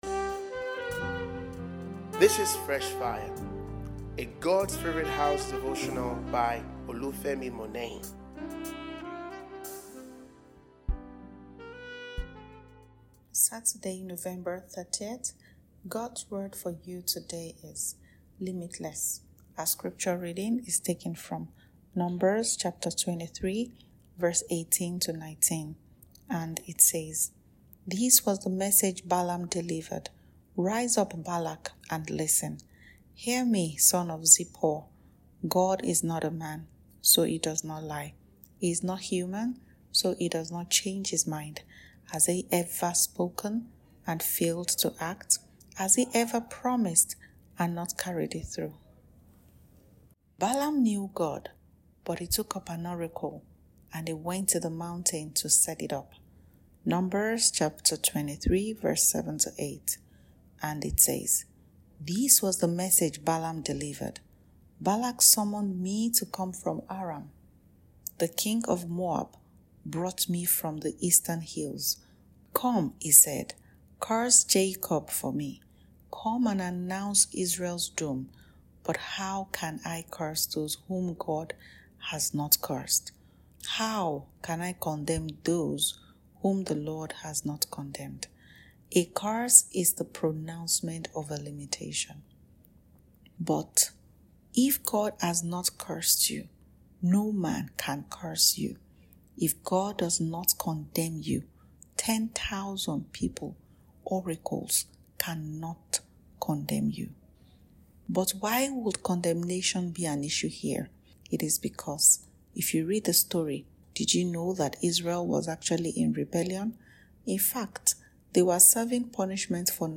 » Limitless Fresh Fire Devotional